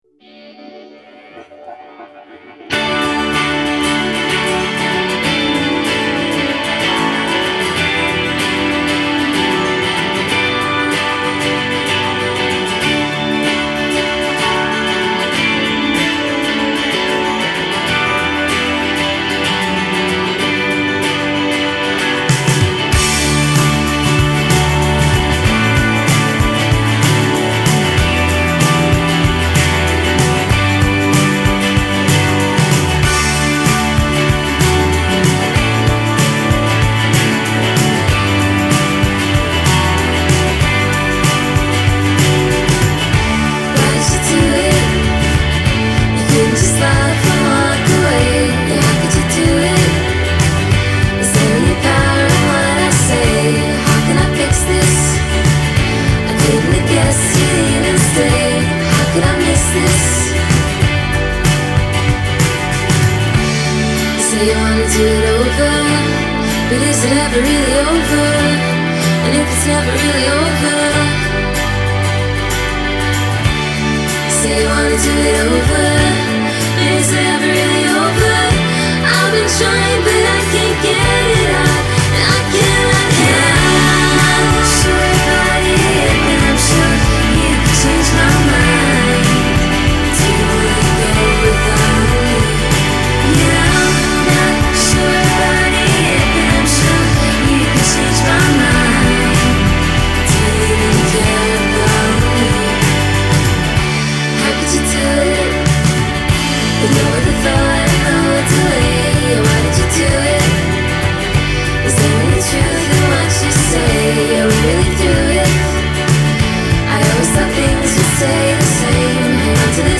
Catchy dream pop